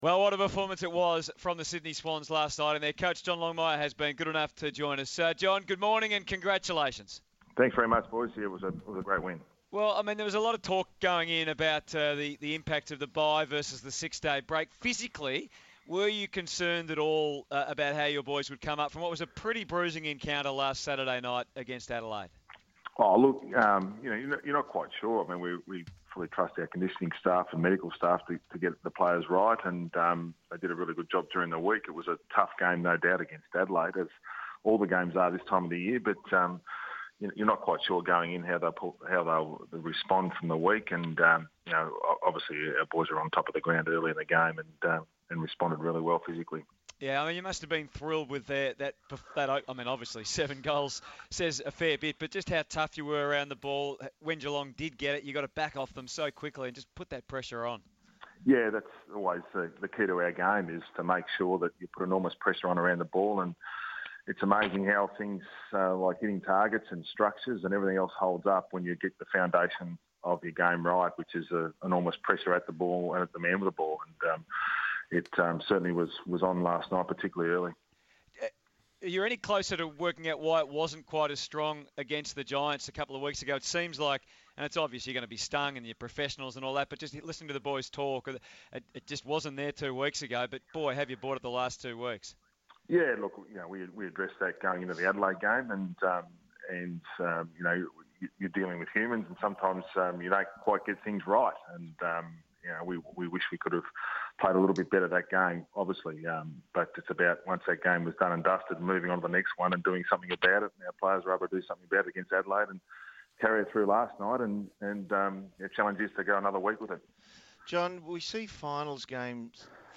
Coach John Longmire speaks to the team on SEN Radio ahead of next Saturday's AFL Grand Final.